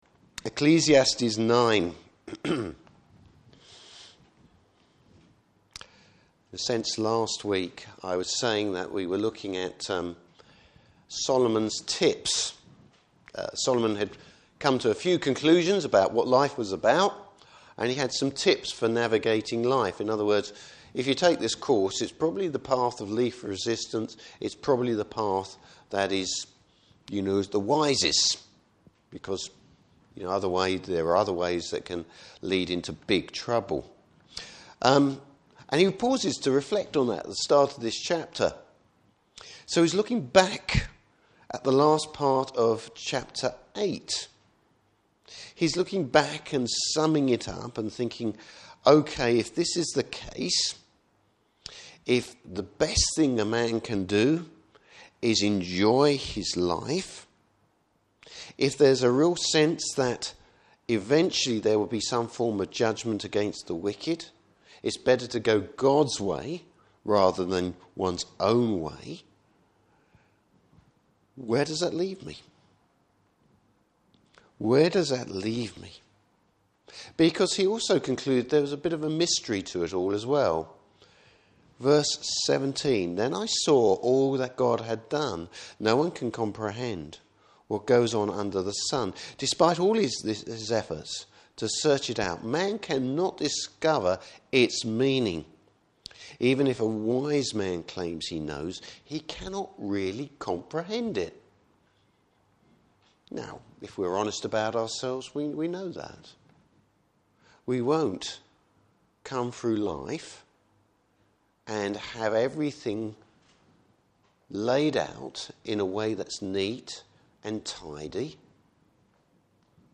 Service Type: Morning Service Bible Text: Ecclesiastes 9.